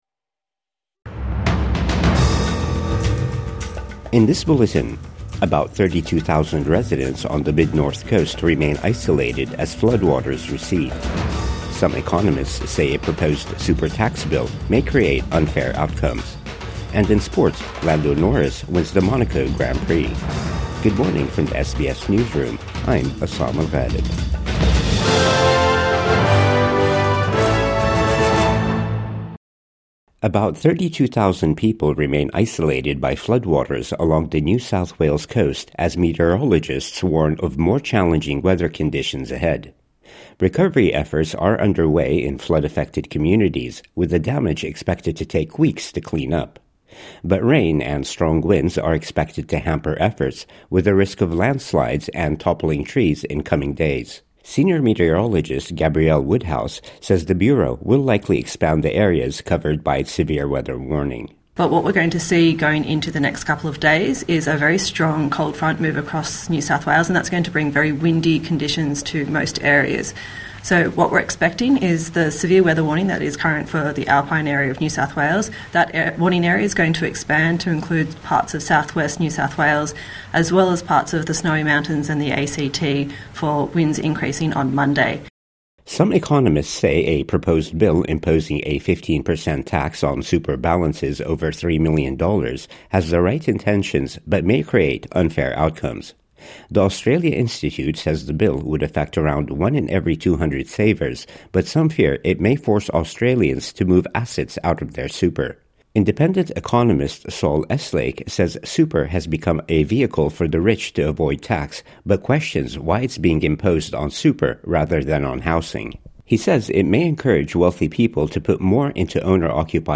Over 30,000 NSW coastal residents still isolated as floods recede | Morning News Bulletin 26 May 2025